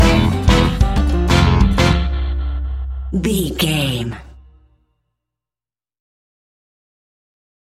Aeolian/Minor
cuban music
World Music
uptempo
brass
saxophone
trumpet